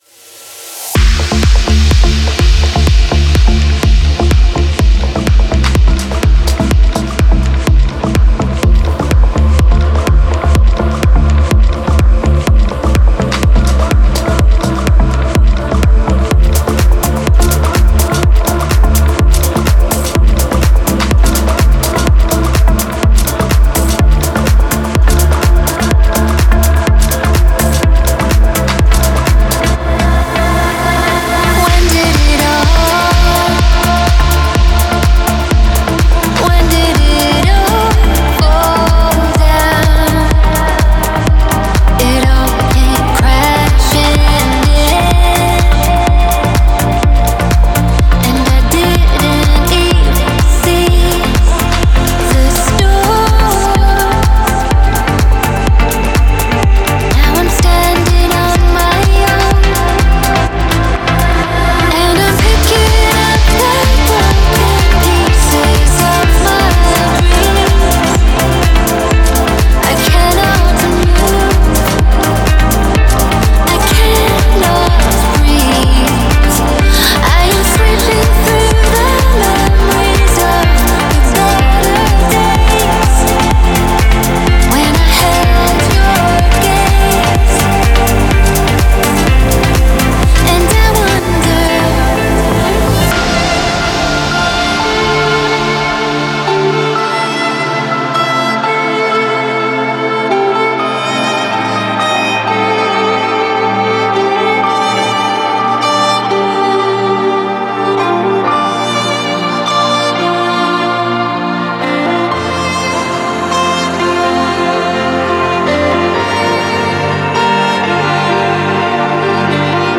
это трек в жанре прогрессивного транс
Эмоциональный вокал